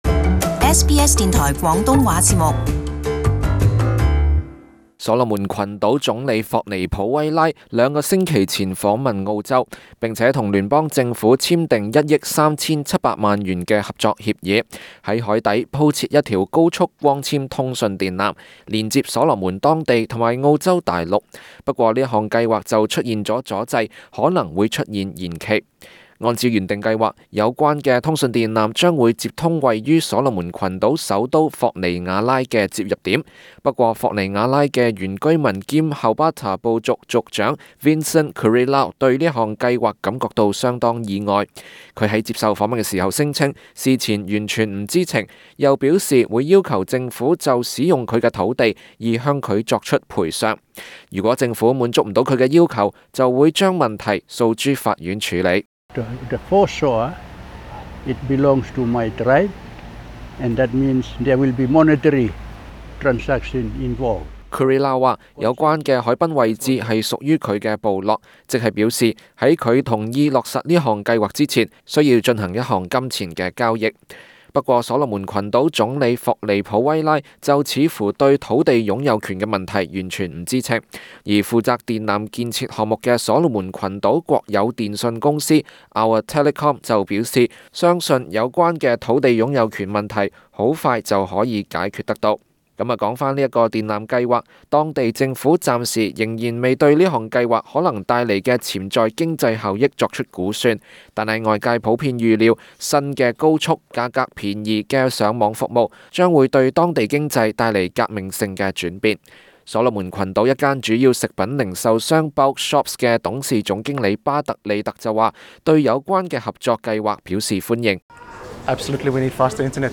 【时事报导】瓦努阿图或求澳洲协助兴建海底电缆